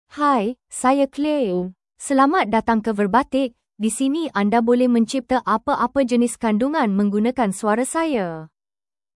Claire — Female Malay (Malaysia) AI Voice | TTS, Voice Cloning & Video | Verbatik AI
Claire is a female AI voice for Malay (Malaysia).
Voice sample
Female
Claire delivers clear pronunciation with authentic Malaysia Malay intonation, making your content sound professionally produced.